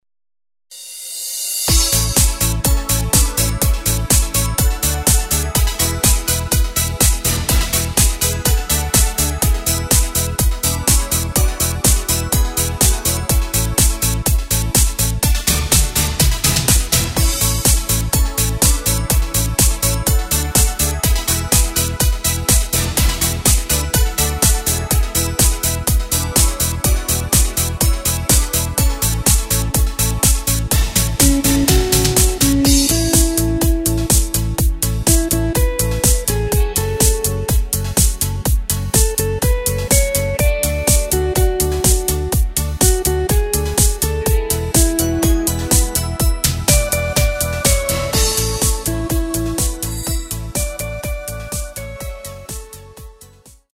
Takt:          4/4
Tempo:         124.00
Tonart:            A
Discofox aus dem Jahr 2015!